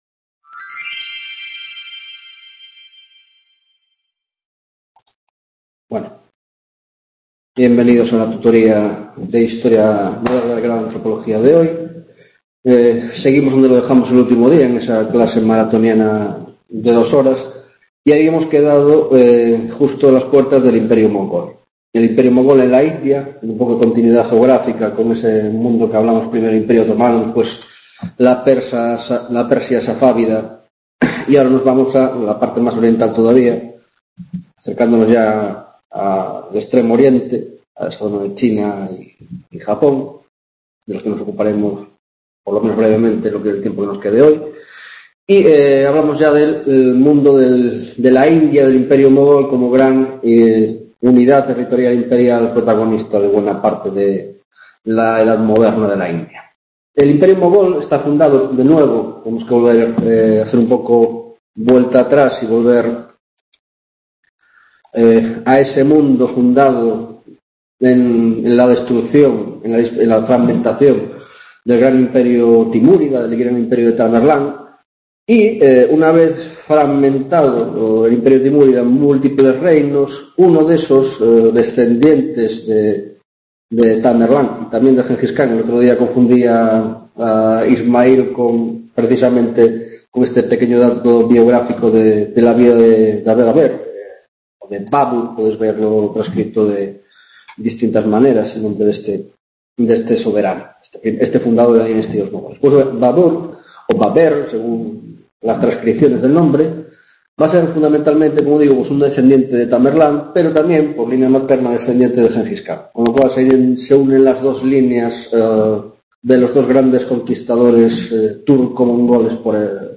10ª tutoría de Historia Moderna, Grado de Antropología - China s. XVI-XVII